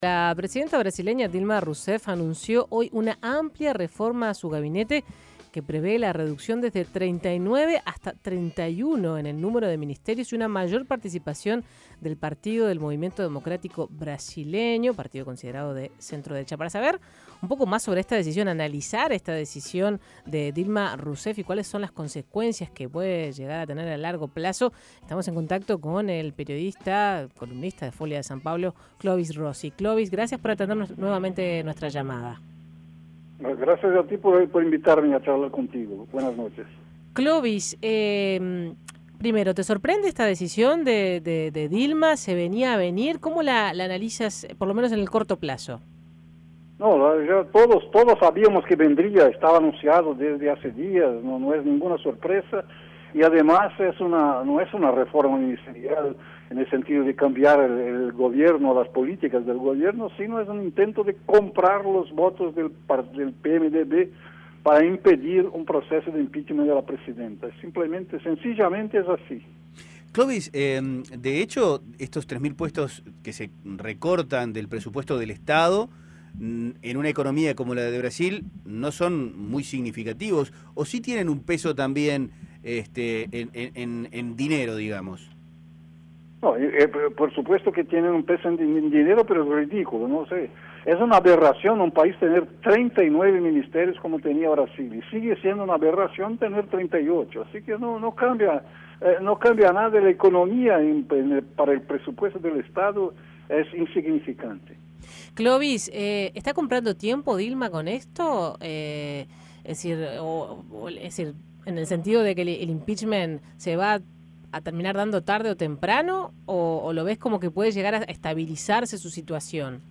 El periodista Clovis Rossi dijo a 810 Vivo que esta reforma no afecta prácticamente el presupuesto del gobierno y aseguró que lo que le otorga respaldo a la presidenta es que el principal enemigo político, que es el presidente de la cámara, es acusado de tener cuentas en Suiza.